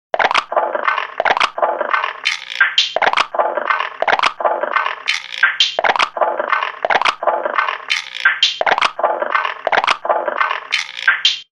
using an E-mu Proteus